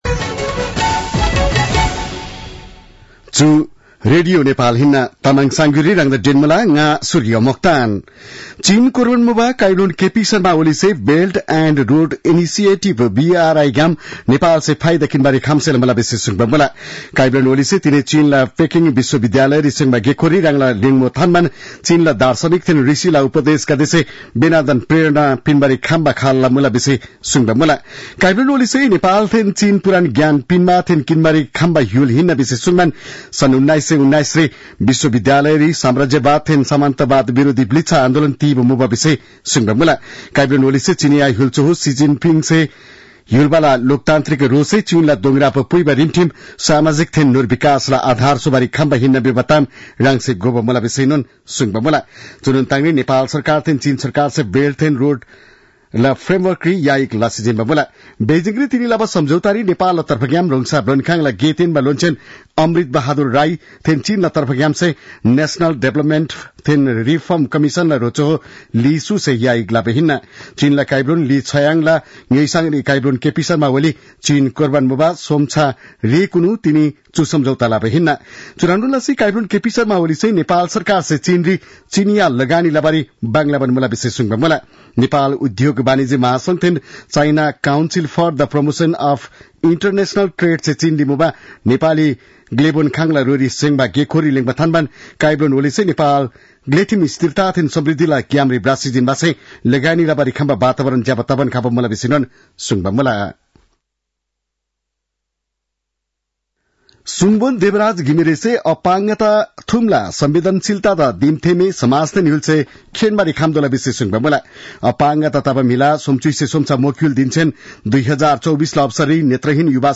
तामाङ भाषाको समाचार : २० मंसिर , २०८१
Tamang-news-8-19.mp3